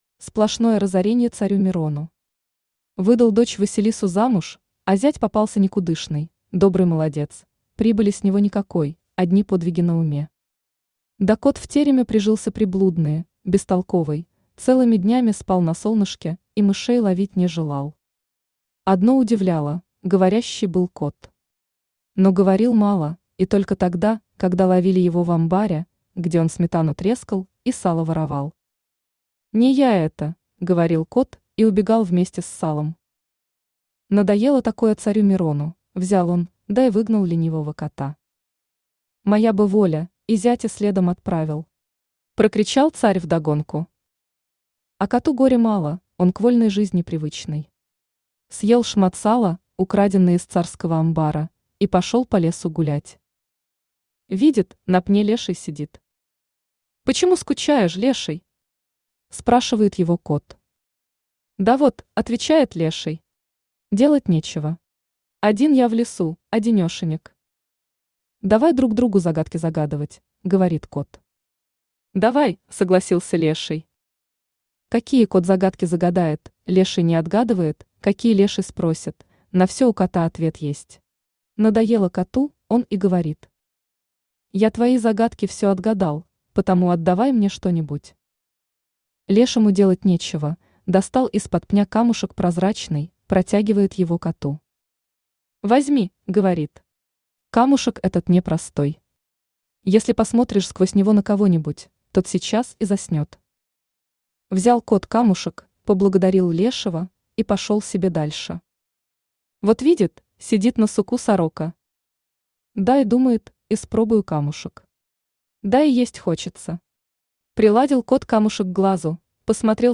Аудиокнига Кот Баюн | Библиотека аудиокниг
Aудиокнига Кот Баюн Автор Алексей Николаевич Наст Читает аудиокнигу Авточтец ЛитРес.